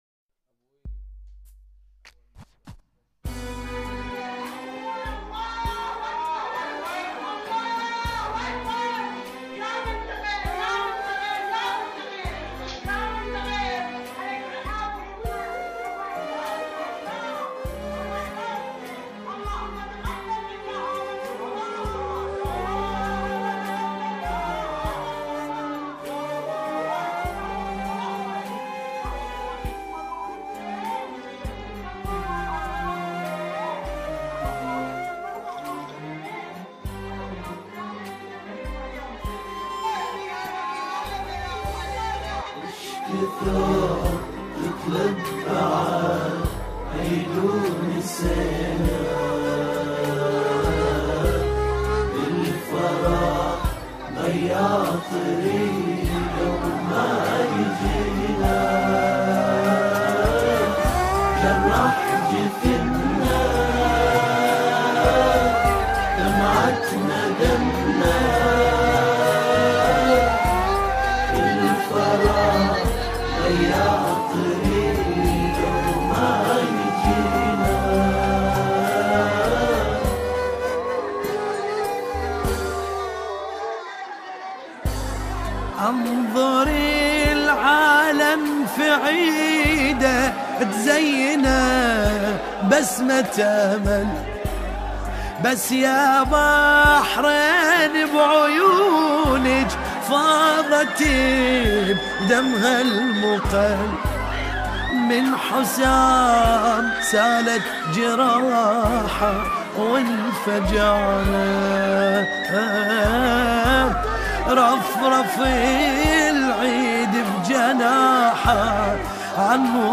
اناشيد وطنية
أناشيد الثورة البحرينية